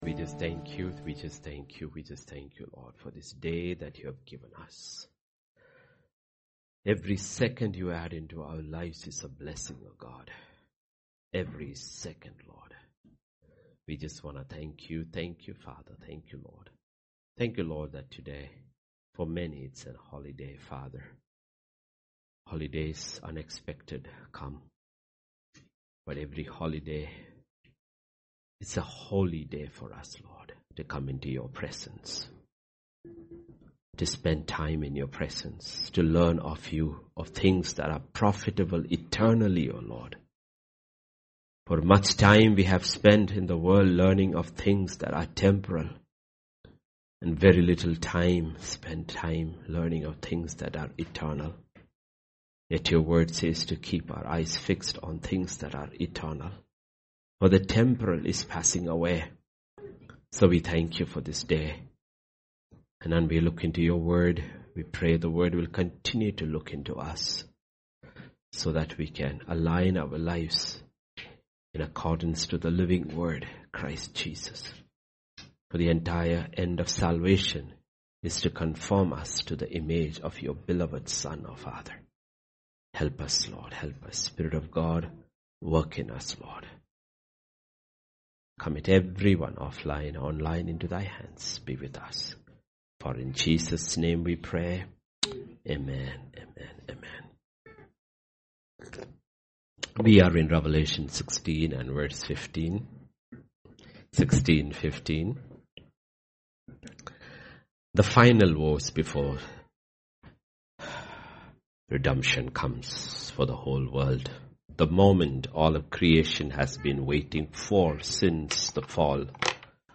Grace Tabernacle Church Hyderabad - Sermons podcast To give you the best possible experience, this site uses cookies.